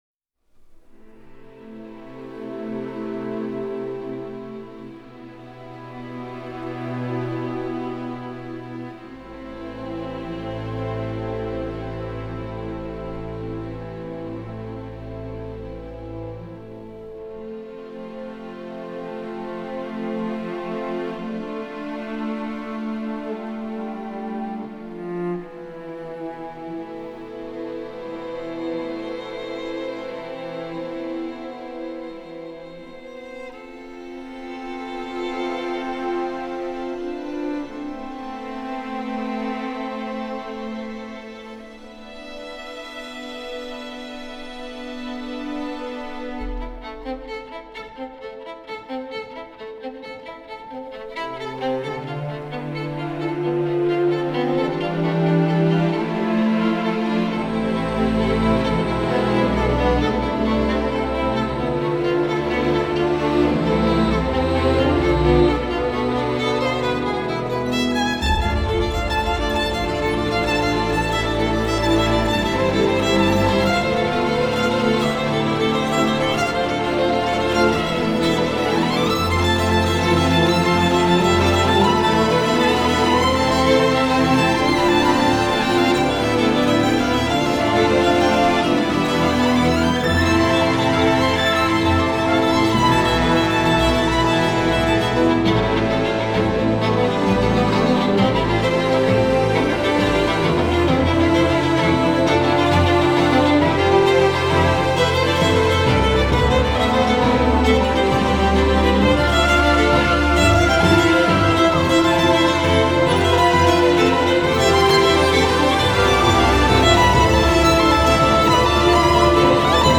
آهنگ ویولون